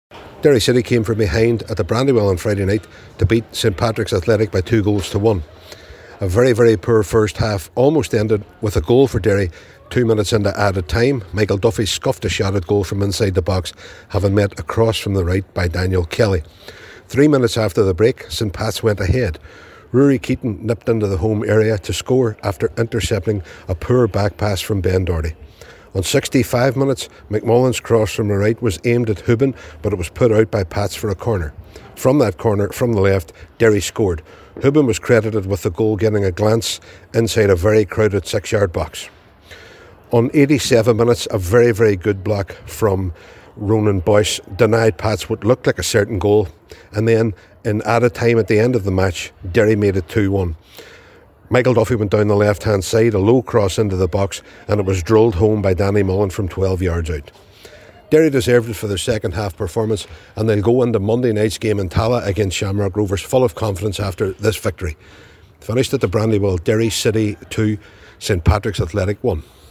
Derry-report1.mp3